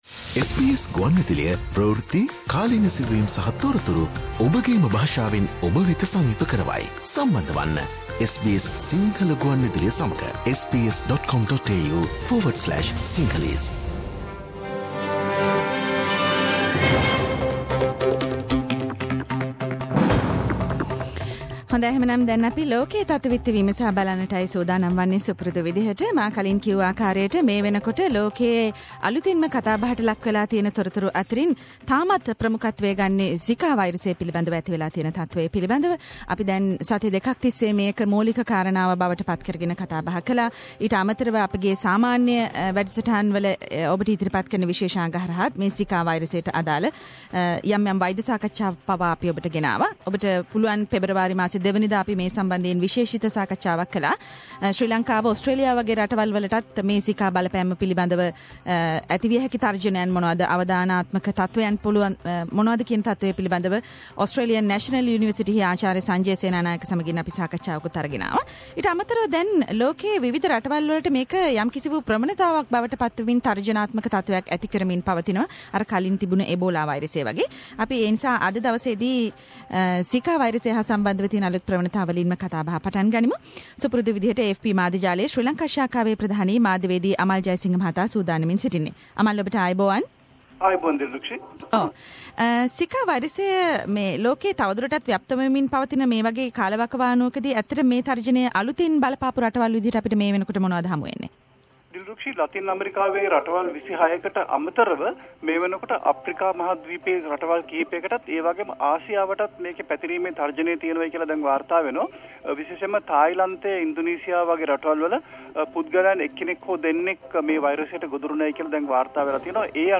SBS Sinhala Around the World - Weekly World News highlights…..